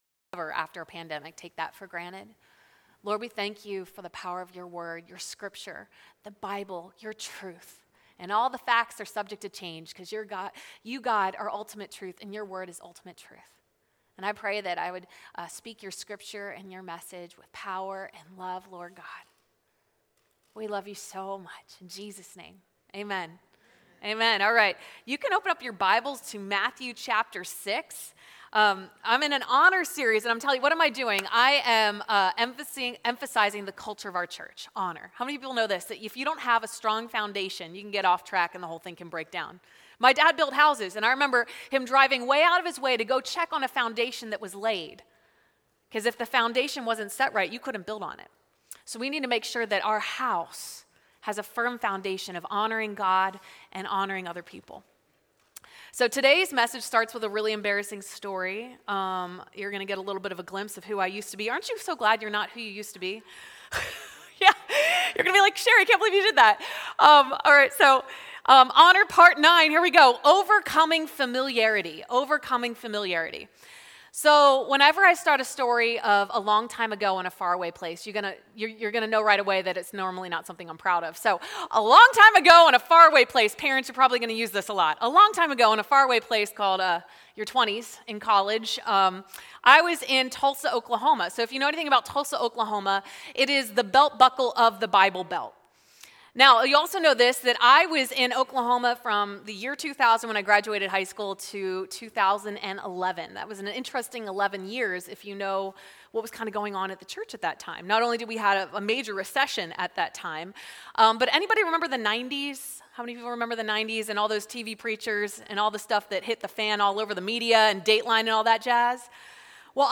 Sunday AM Service